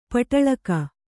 ♪ paṭaḷaka